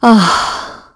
Isolet-Vox_Sigh2_kr.wav